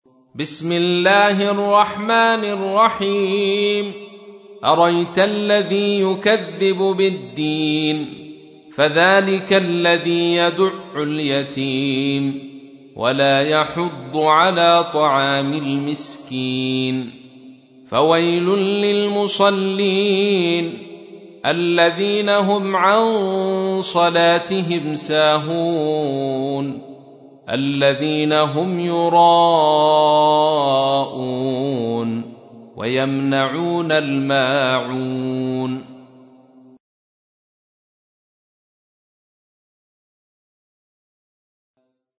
تحميل : 107. سورة الماعون / القارئ عبد الرشيد صوفي / القرآن الكريم / موقع يا حسين